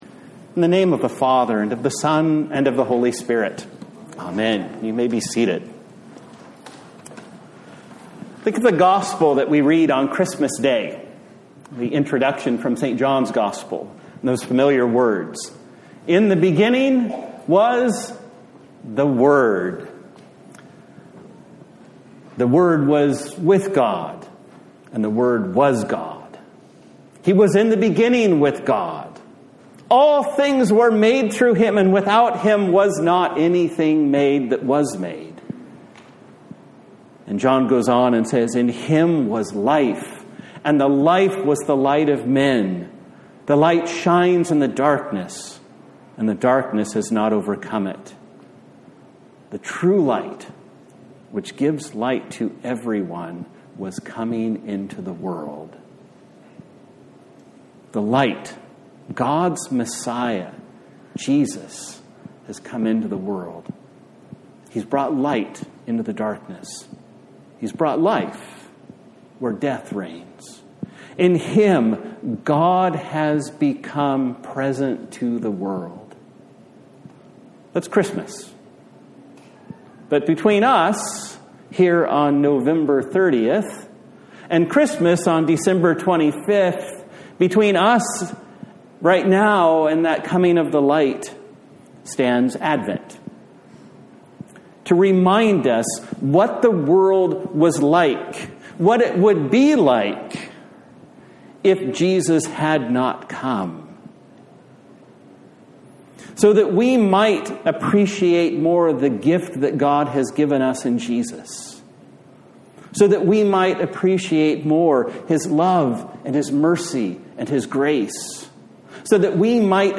A Sermon for the First Sunday in Advent
Service Type: Sunday Morning